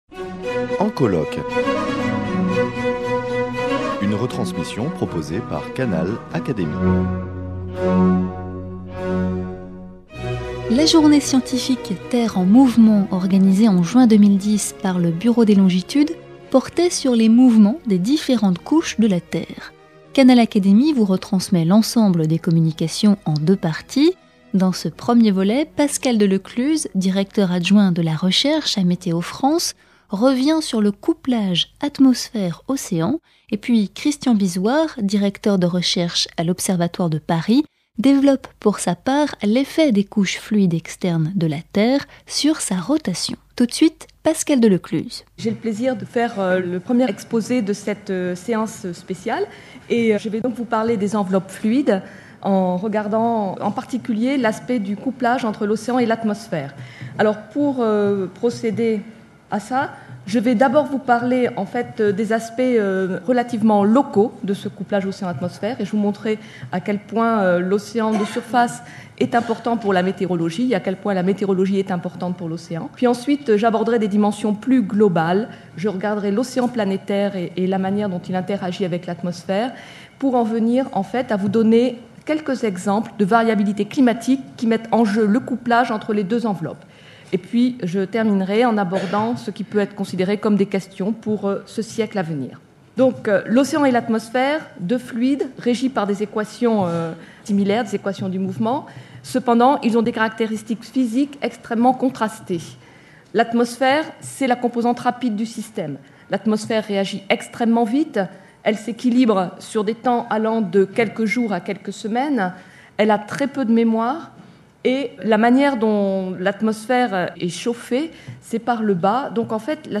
Le colloque Terre en mouvements organisé en juin 2010 par le Bureau des longitudes porte sur les mouvements des différentes couches de la Terre. Canal Académie vous retransmet l’ensemble des communications en deux parties.